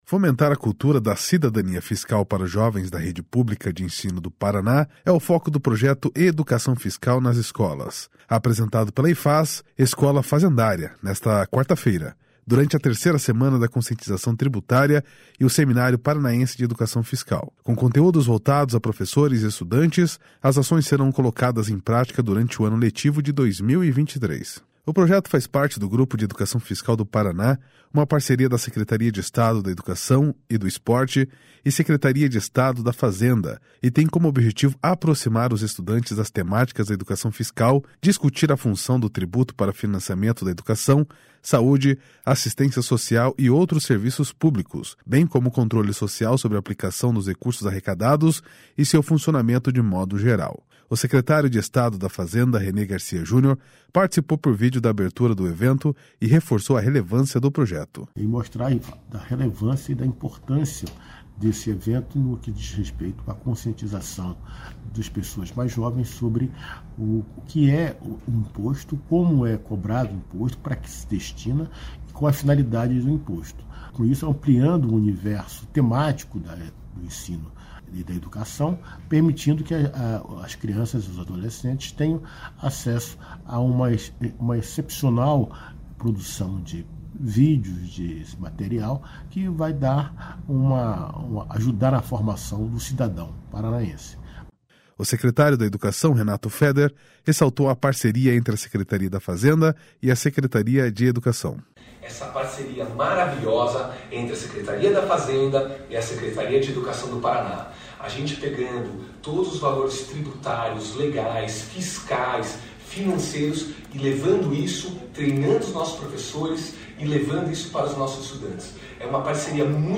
O secretário da Educação, Renato Feder, ressaltou a parceria entre a Secretaria da Fazenda e a Secretaria de Educação.//SONORA RENATO FEDER//